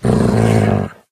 1.21.4 / assets / minecraft / sounds / mob / wolf / growl2.ogg
growl2.ogg